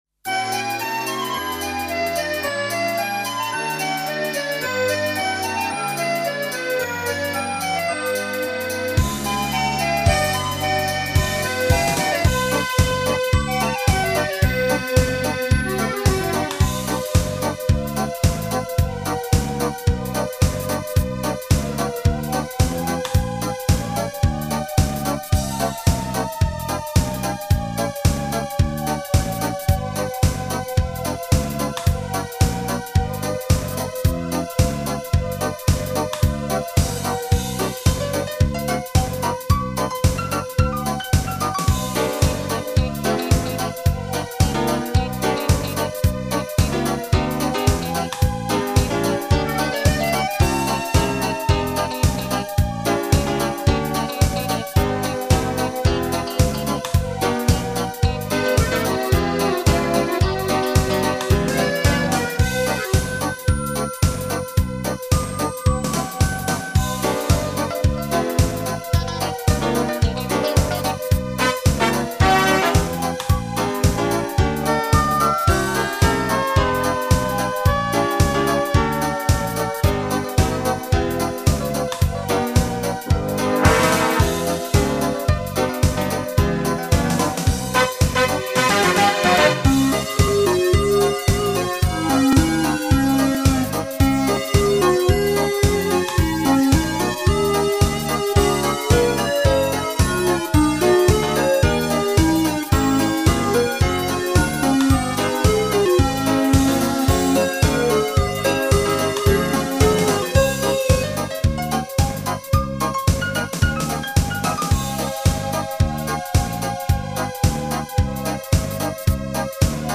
минус